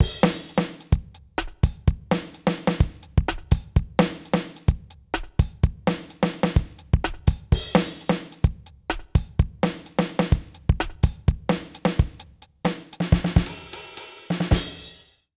British ROCK Loop 124BPM.wav